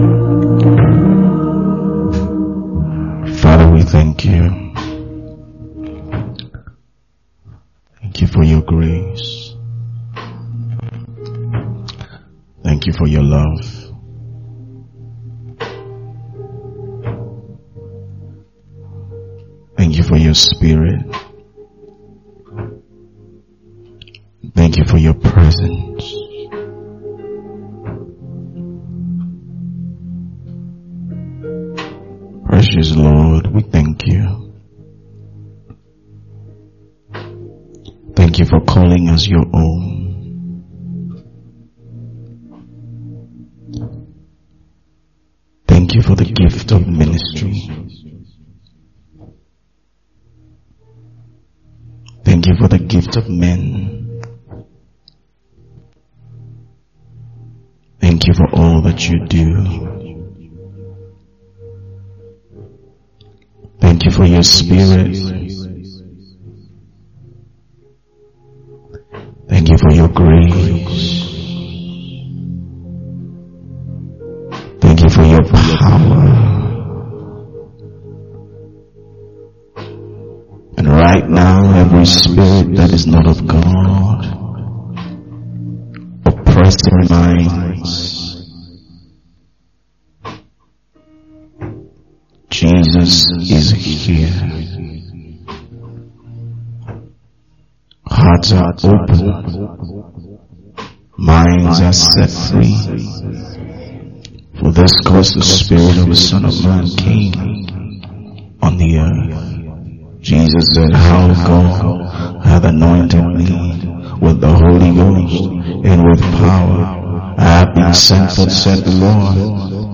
A teaching